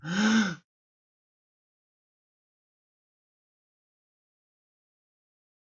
自制的声音效果 " 最后的声音 茎的吸入
描述：呼吸
Tag: 呼吸式 吸气 喘气